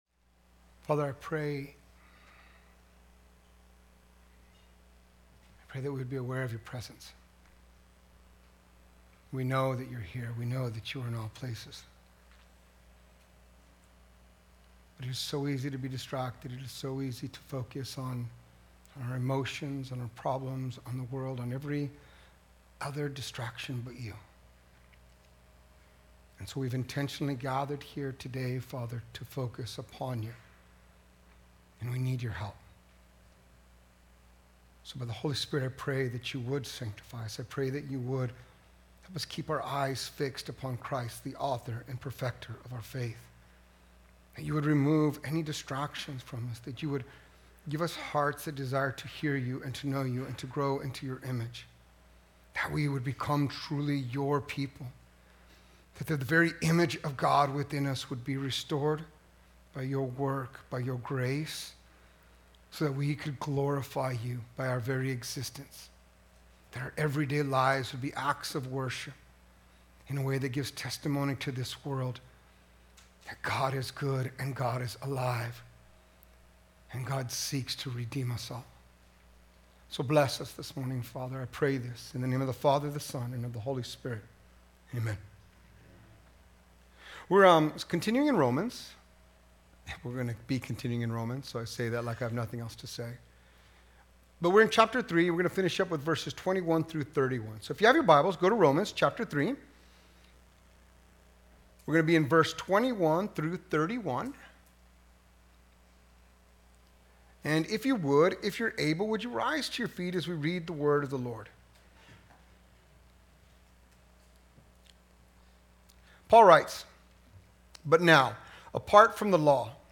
Sermons | Mercy Springs Church of the Nazarene